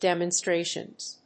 /ˌdɛmʌˈnstreʃʌnz(米国英語), ˌdemʌˈnstreɪʃʌnz(英国英語)/
demonstrations.mp3